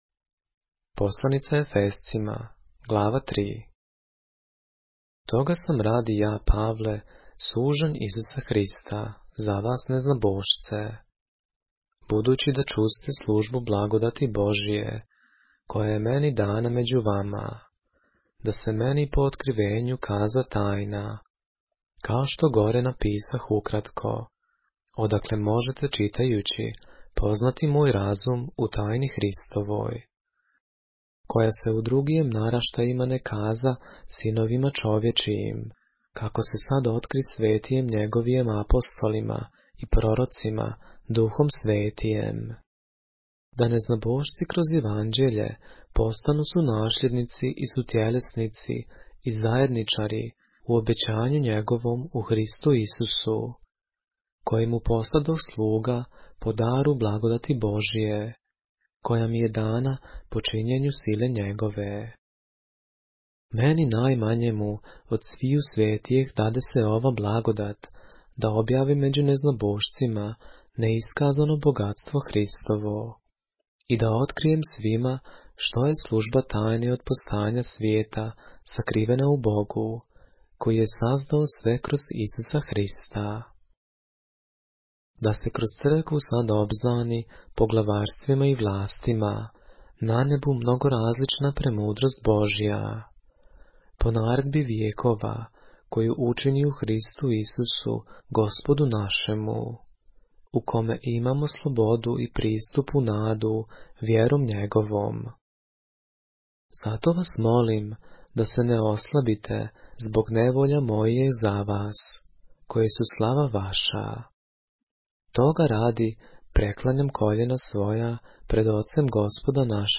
поглавље српске Библије - са аудио нарације - Ephesians, chapter 3 of the Holy Bible in the Serbian language